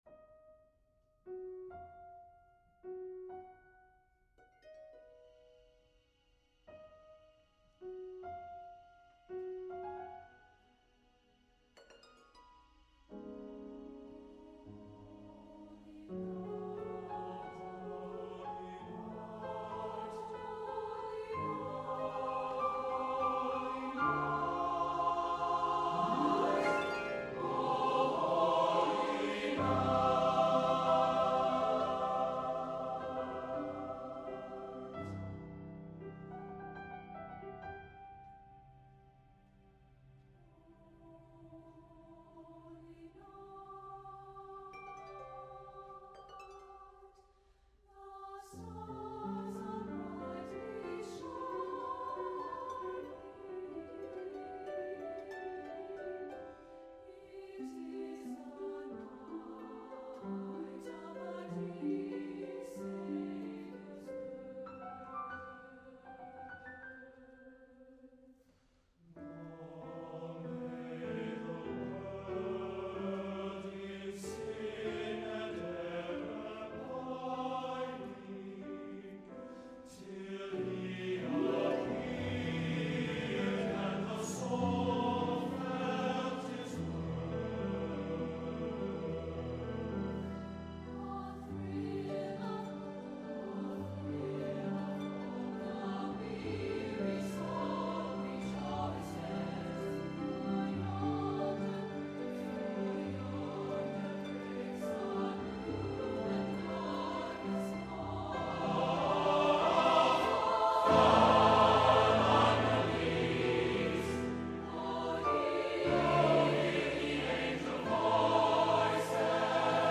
Accompaniment:      With Piano
Music Category:      Choral
Can be performed with piano or synthesizer.